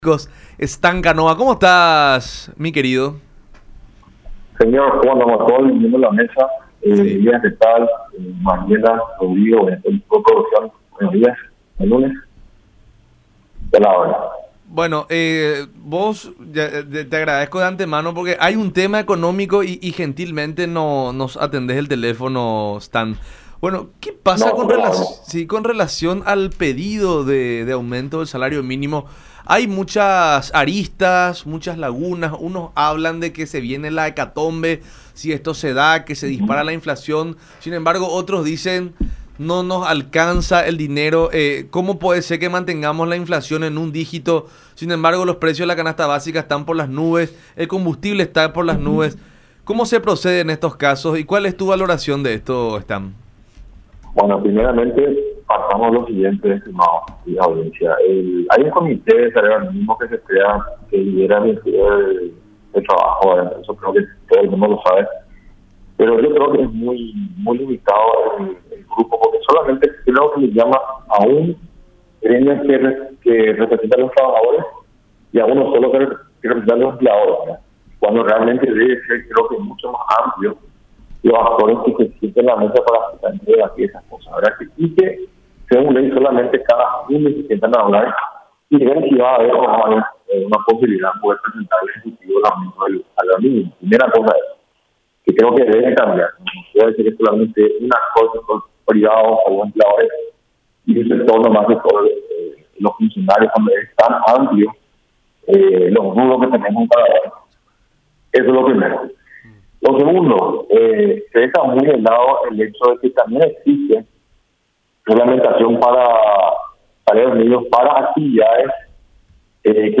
en diálogo con La Unión Hace La Fuerza a través de Unión TV y radio La Unión.